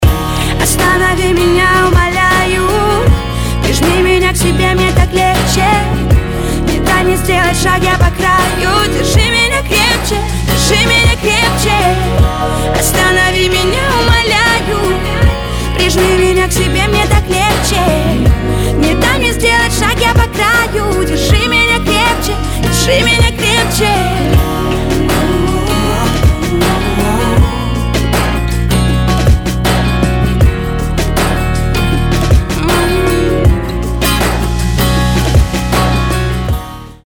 романтика